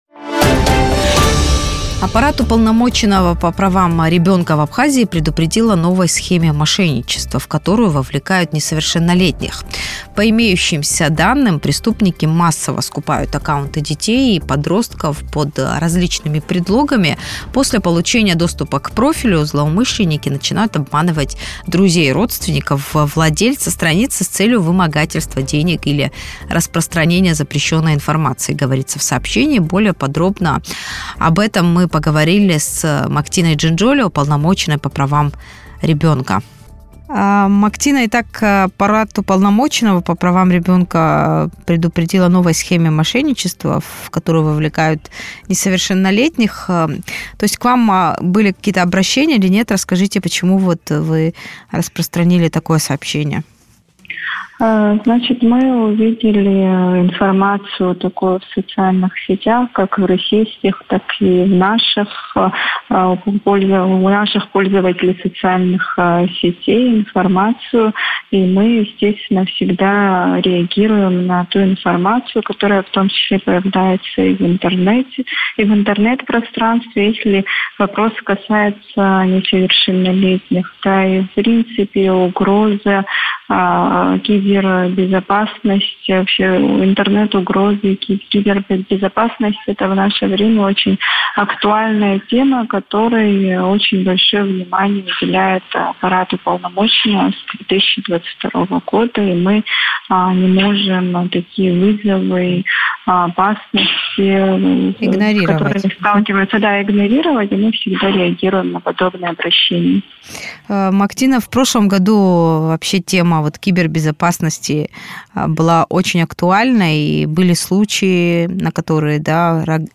Уполномоченный по правам ребенка в Абхазии Мактина Джинджолия в интервью радио Sputnik рассказала, как защитить детей и подростков от мошенничества в сети интернет.